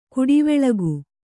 ♪ kuḍiveḷagu